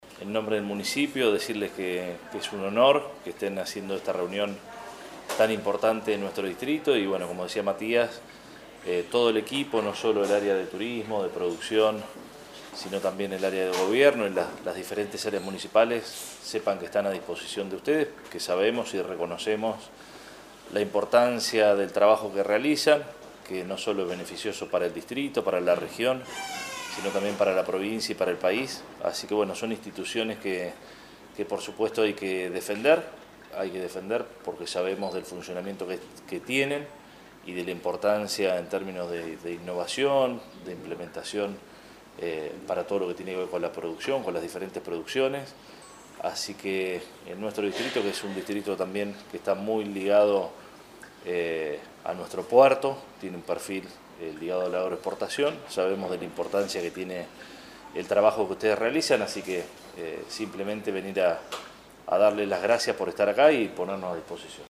En la jornada de este jueves 30 de octubre, la Secretaría de Turismo y Desarrollo Productivo de la Municipalidad de Necochea fue sede de un nuevo encuentro del Consejo Local Asesor (CLA) del INTA Balcarce, que reunió a instituciones públicas y privadas del sistema de innovación agropecuaria de la región Mar y Sierras, con el objetivo de fortalecer el trabajo conjunto y la articulación territorial.
En la apertura, el intendente Arturo Rojas dio la bienvenida a los participantes y destacó la importancia del encuentro: “Es un honor que lleven adelante esta reunión en nuestro distrito. Todo el equipo municipal está a disposición de ustedes porque reconocemos la importancia del trabajo que realizan, que no solo es beneficioso para Necochea y la región, sino también para la provincia y el país”, expresó el jefe comunal.
30-10-AUDIO-Arturo-Rojas.mp3